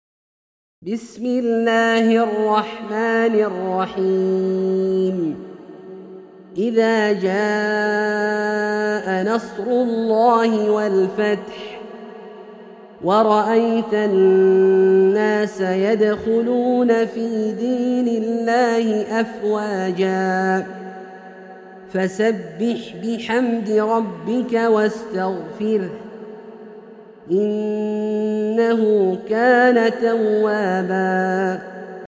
سورة النصر - برواية الدوري عن أبي عمرو البصري > مصحف برواية الدوري عن أبي عمرو البصري > المصحف - تلاوات عبدالله الجهني